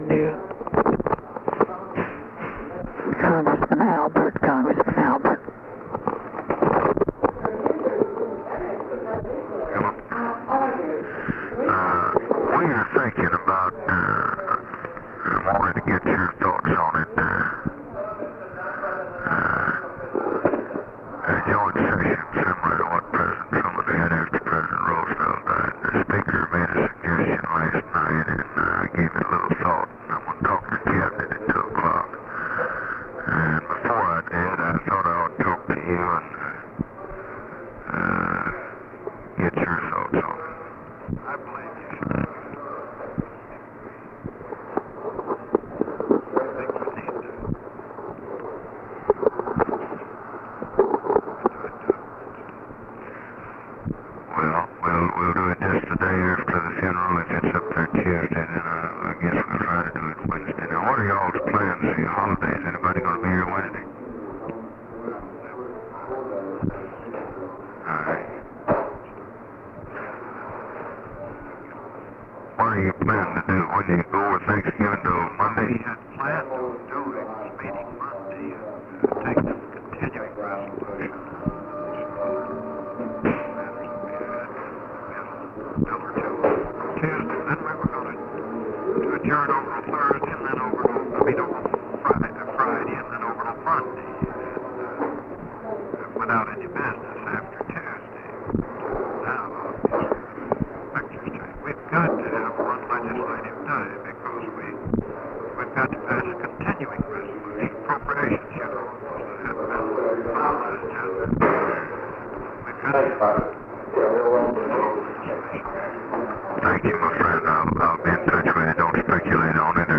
Conversation with CARL ALBERT, November 23, 1963
Secret White House Tapes